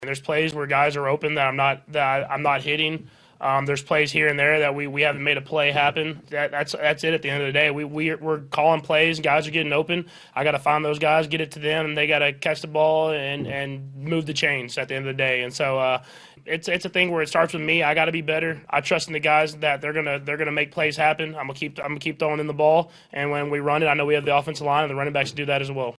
Chiefs quarterback Patrick Mahomes says it comes down to executing.
11-14-patrick-mahomes.mp3